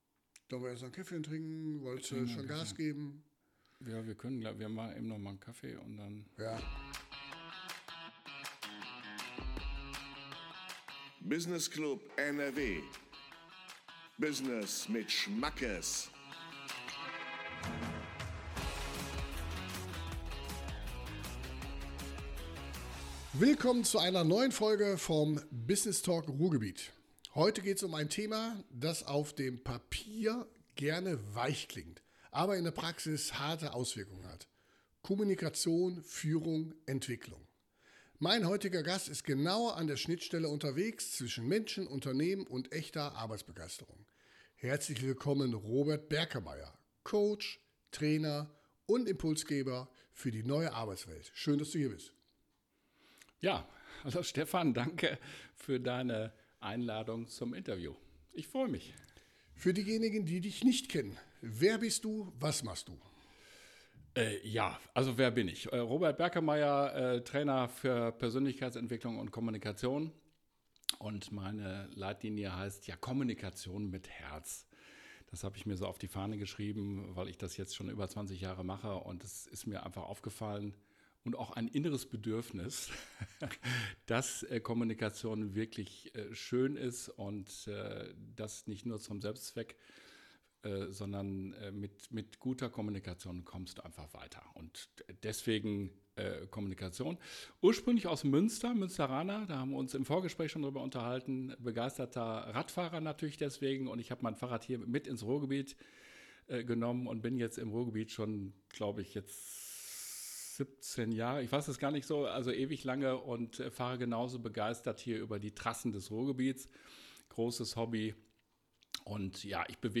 Ich war zu Gast im Podcast - so geht's ab in meinem Unternehmen!